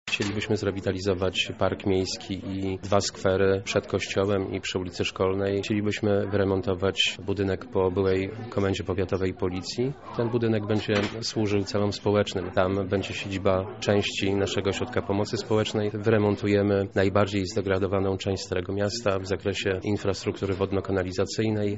— mówi Jarosław Żaczek, burmistrz Ryk.